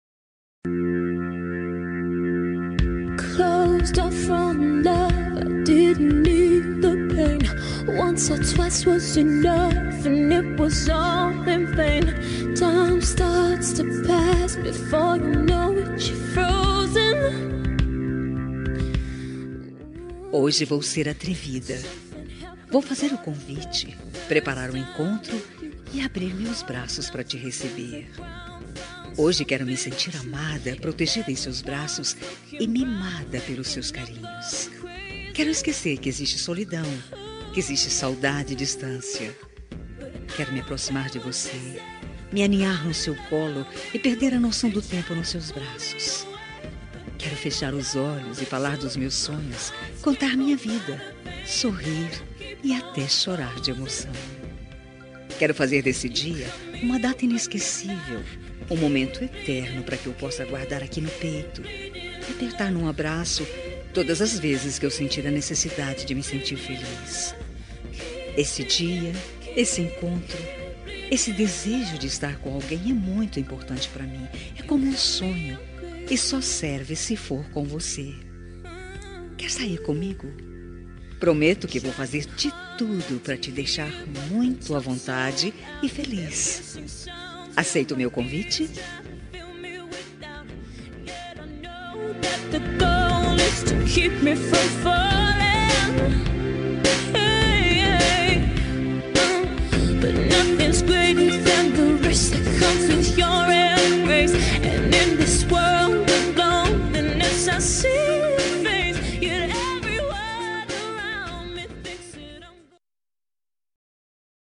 Telemensagem de Pedido – Voz Masculina – Cód: 4167 -Quer sair comigo